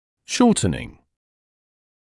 [‘ʃɔːtnɪŋ][‘шоːтнин]укорочение, уменьшение в длине
shortening.mp3